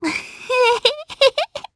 Viska-Vox_Happy2_jp_b.wav